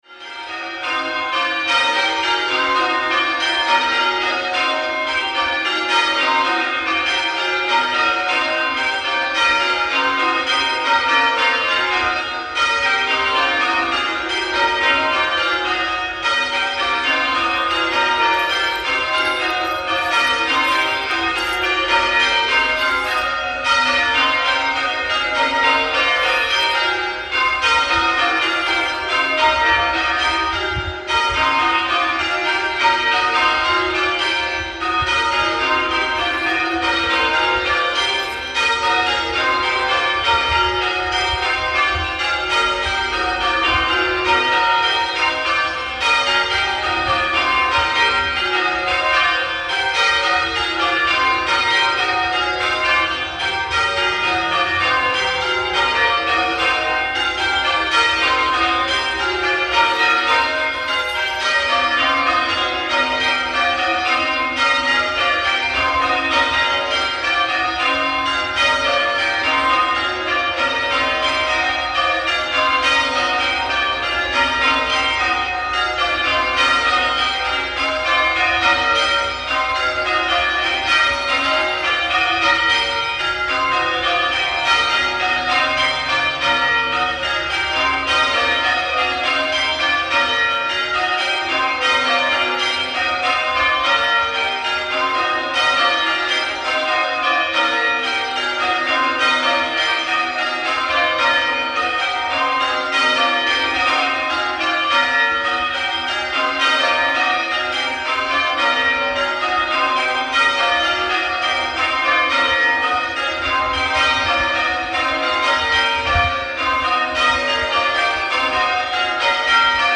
ThebertonSt Peter